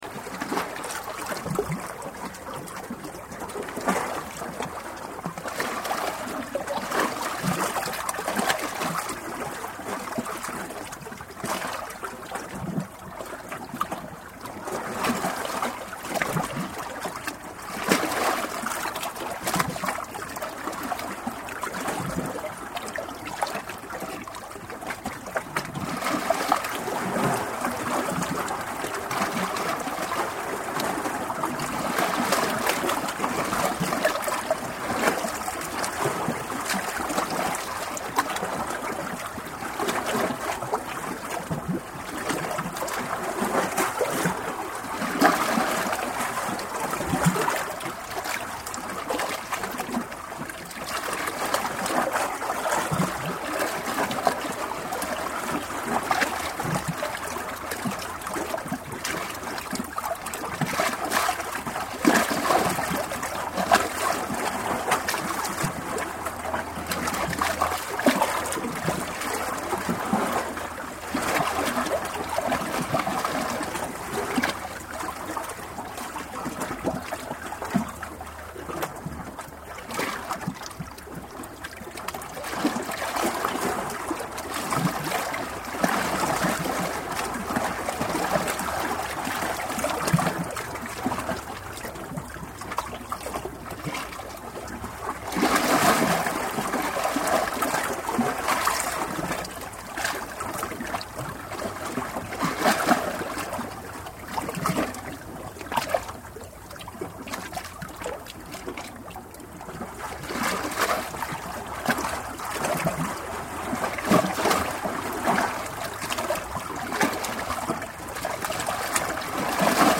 Sea-waves-crashing-into-rocks.mp3